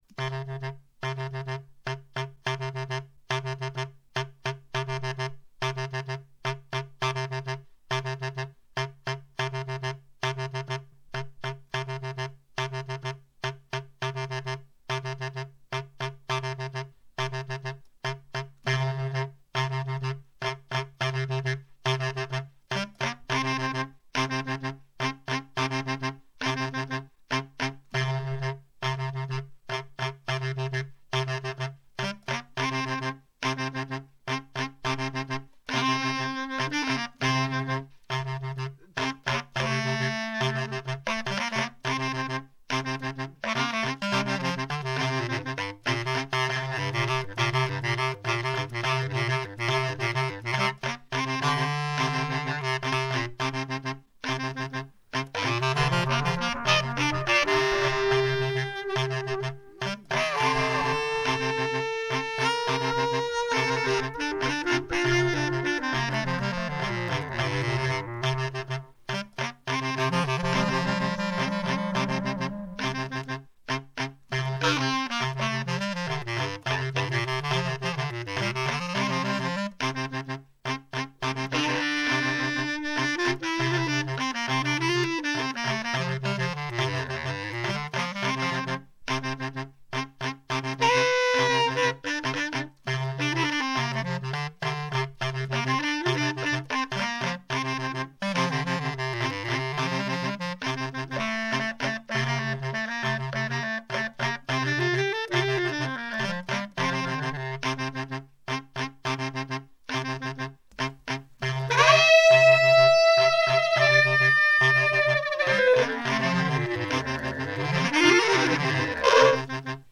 solo bass clarinet 3
Recorded live at home in Manhattan.
bass clarinet, samplers
samplers mono